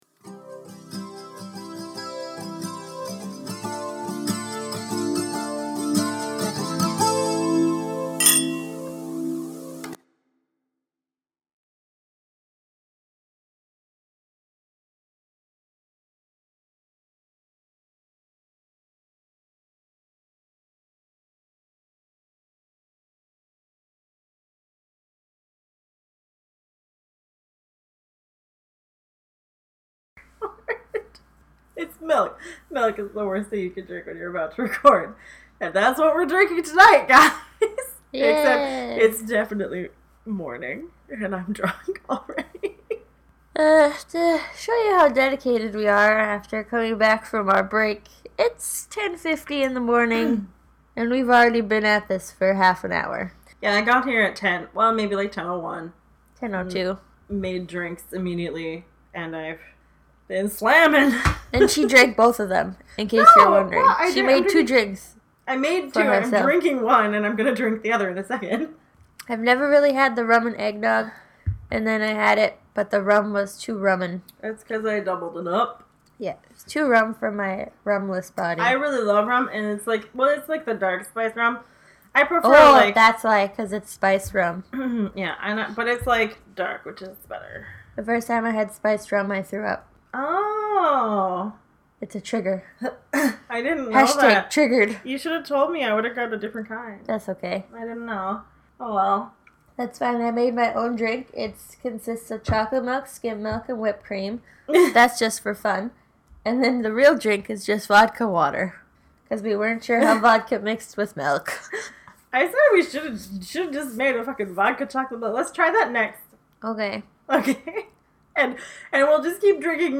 Ever wonder what happens when two chicks get drunk at 10 am? They watch a trashy winter horror movie about a snowman and try to figure out if meth can be snorted or not.